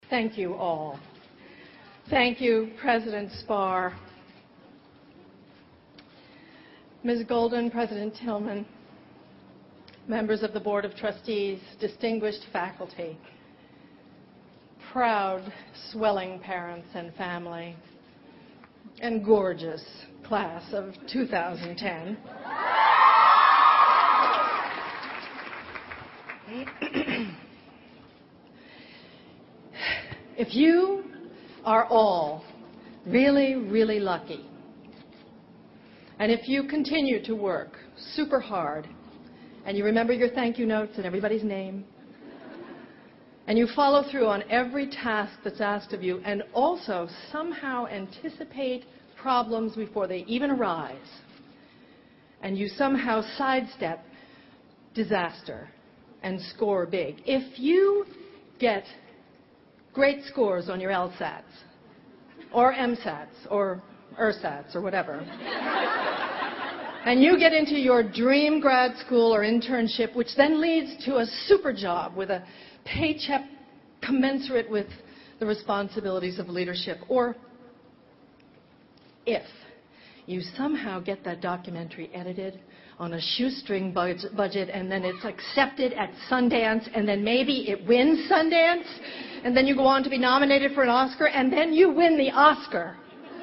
在线英语听力室偶像励志英语演讲 41:让父母为你骄傲(1)的听力文件下载,《偶像励志演讲》收录了娱乐圈明星们的励志演讲。